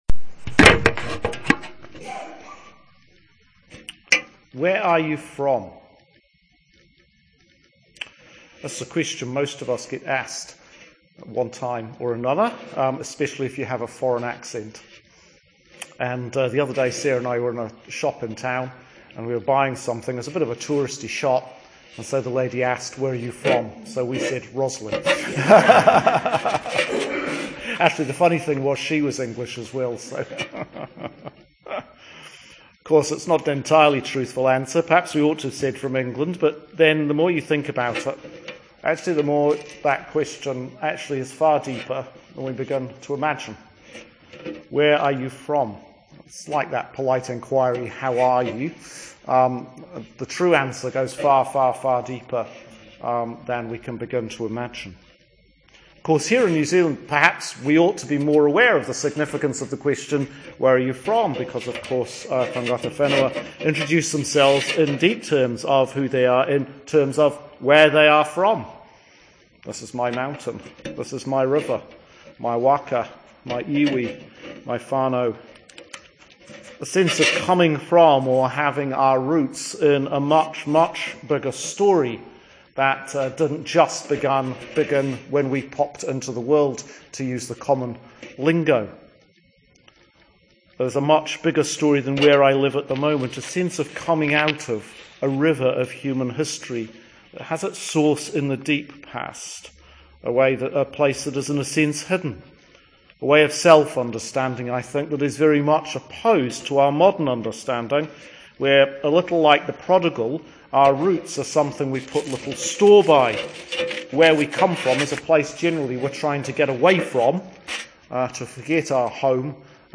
Sermon for Advent 3 – Year B – 2017 1 Thessalonians 5:12-24 John 1:6-8,19-28